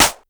snr_73.wav